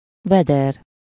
Ääntäminen
IPA: /ˈvɛdɛr/